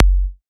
edm-kick-26.wav